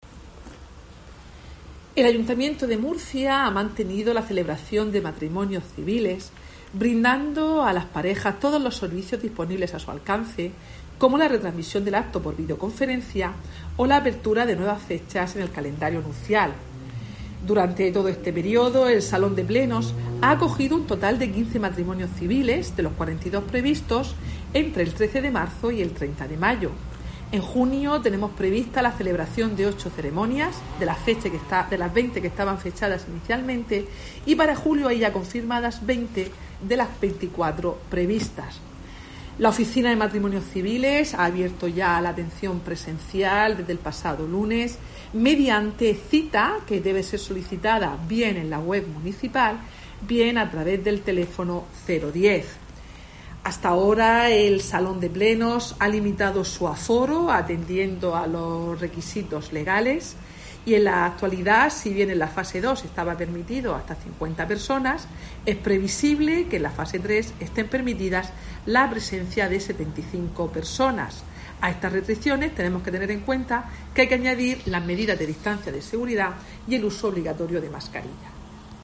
Mercedes Bernabé, concejala de Agenda Urbana y Gobierno Abierto